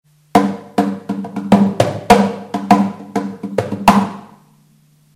SCHLAGWERK Comparsa cajon with 2 playing surfaces